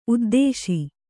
♪ uddēśi